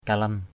kalan.mp3